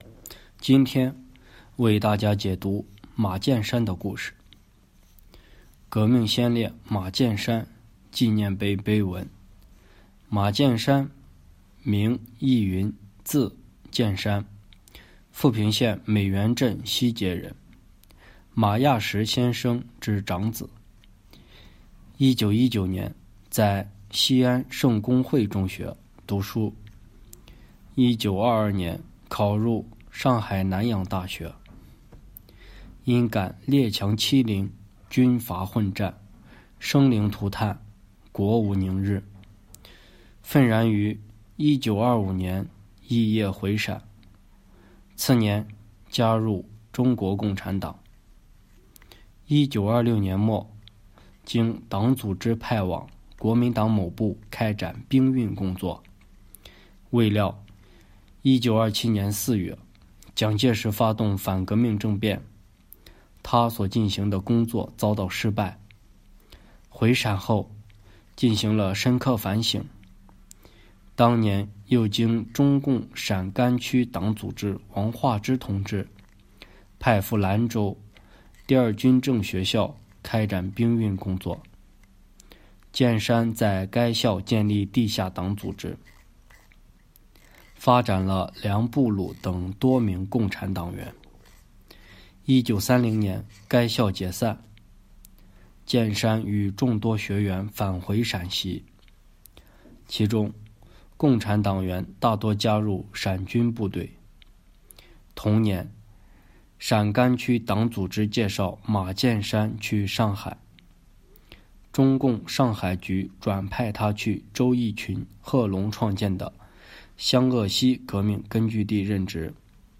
【红色档案诵读展播】革命先烈马建山纪念碑碑文